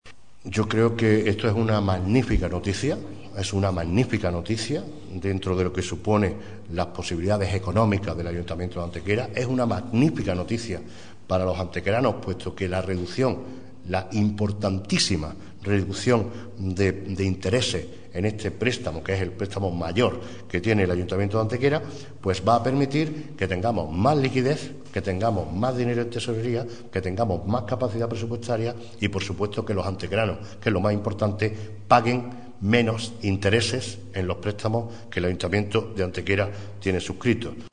El alcalde de Antequera, Manolo Barón, ha anunciado hoy en rueda de prensa que la Junta de Gobierno Local ha aprobado la adjudicación de la refinanciación de los préstamos ICO a proveedores –que tenía suscritos el Ayuntamiento de Antequera– a la Caja Rural del Sur como resultado del concurso negociado con varias entidades financieras que había planteado tanto la Alcaldía como la Tesorería del Consistorio con el objetivo de seguir mejorando las condiciones financieras de dicha operación.
Cortes de voz